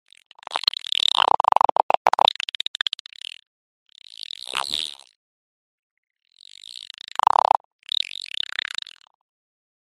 Alien Voices Distorted, otherworldly dialogue "Layered insectoid clicks with echo and shifting pitch"